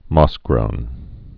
(môsgrōn, mŏs-)